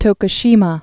(tōkə-shēmä)